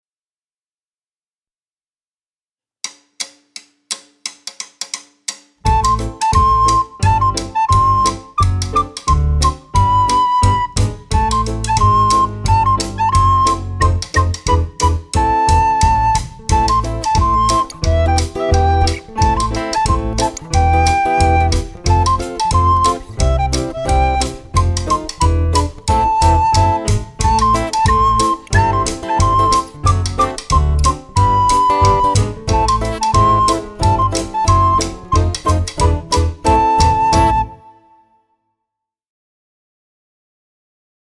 Besetzung: Instrumentalnoten für Blockflöte